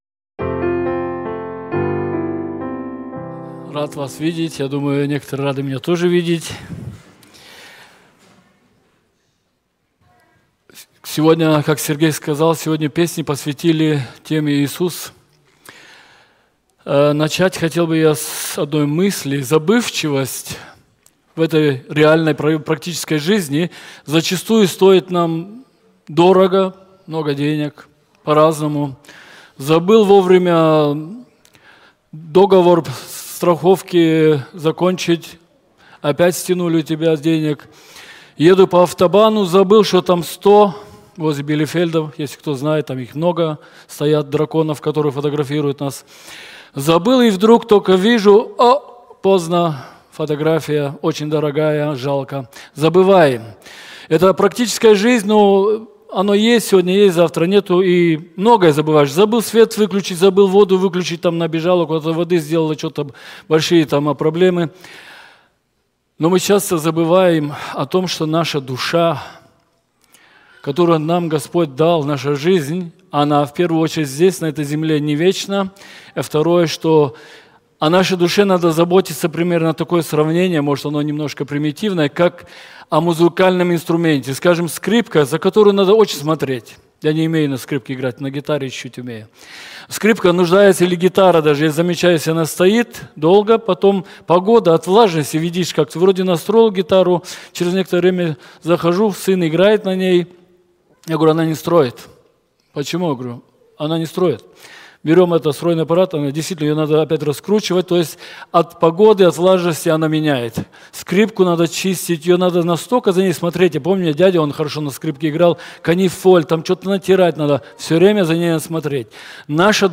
Russischer Gottesdienst